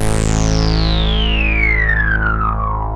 KORG A1 1.wav